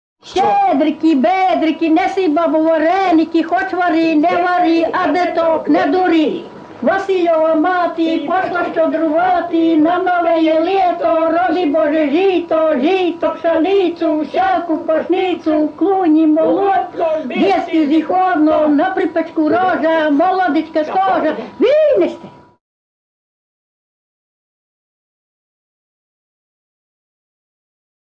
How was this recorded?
Authentic Performing